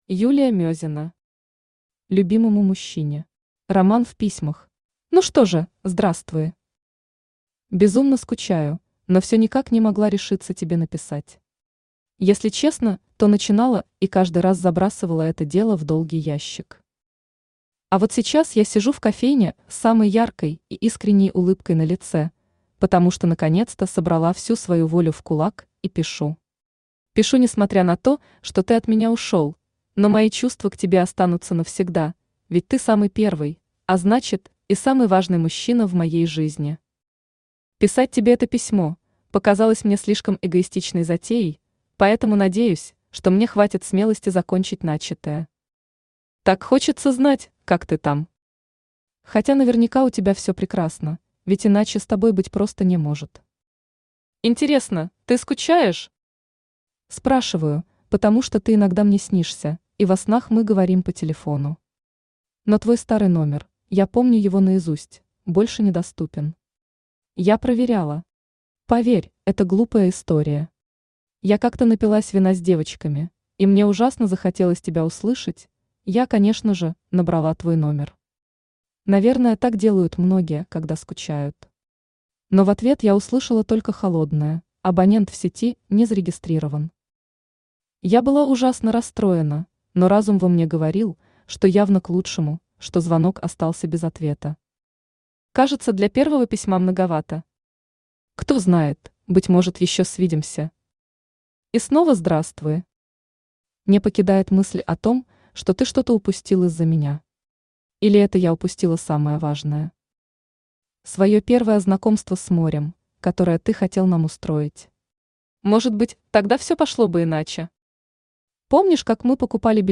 Аудиокнига Любимому мужчине. Роман в письмах | Библиотека аудиокниг
Роман в письмах Автор Юлия Мезина Читает аудиокнигу Авточтец ЛитРес.